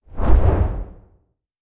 FireBall_1.wav